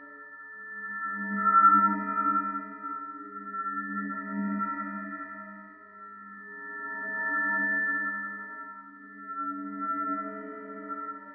chimes.wav